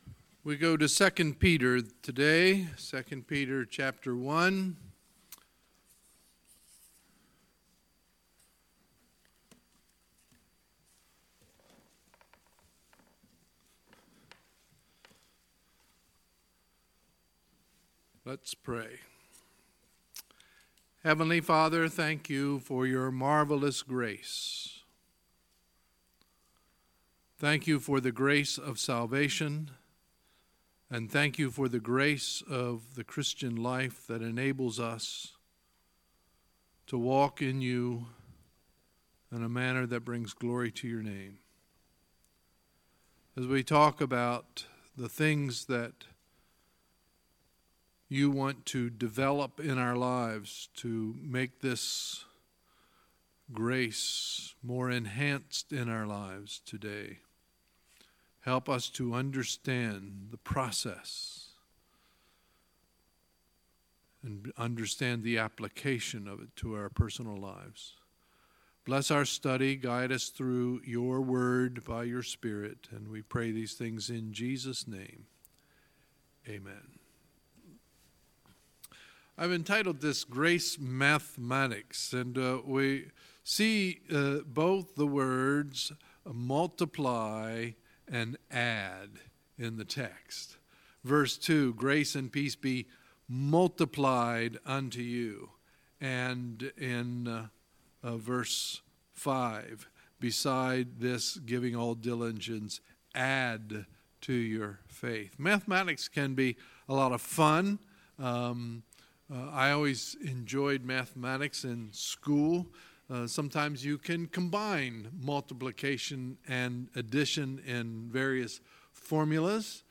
Sunday, September 2, 2018 – Sunday Morning Service